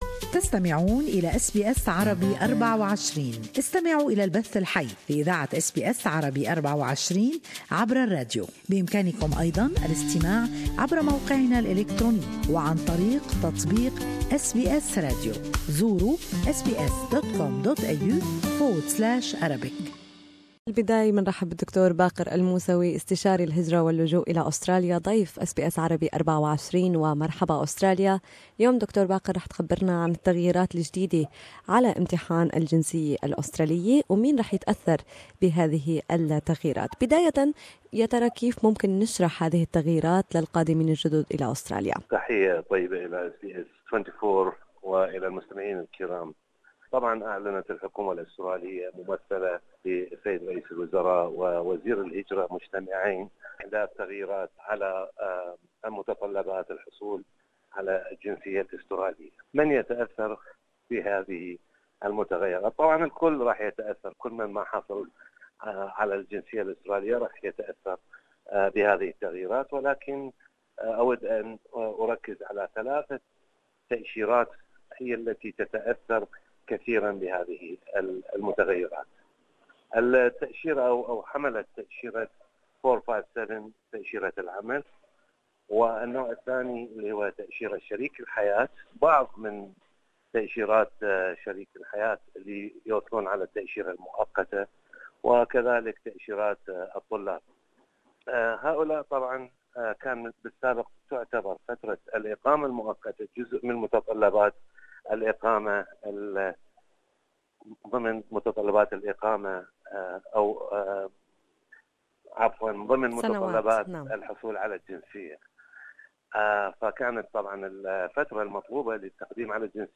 والتفاصيل في المقابلة الصوتية.